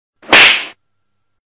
Quick Lil Slap